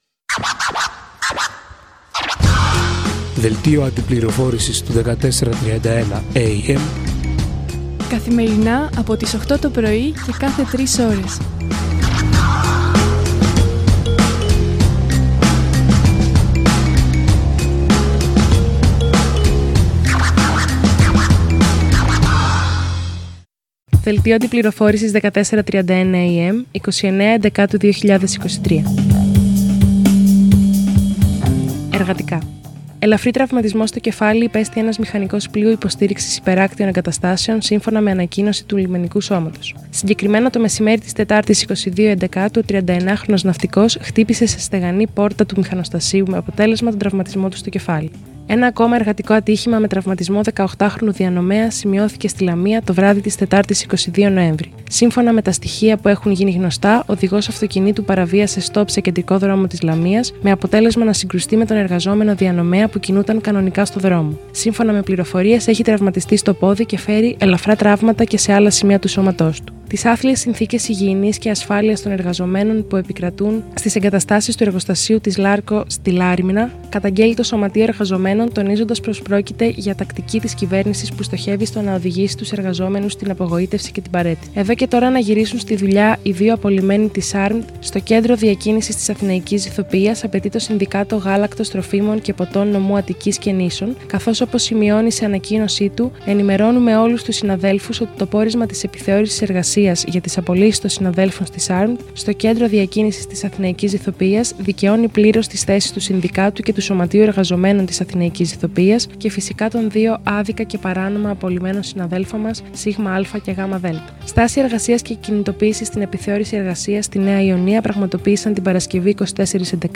Δελτίο αντιπληροφόρησης 30/11 - ελεύθερο κοινωνικό ραδιόφωνο 1431AM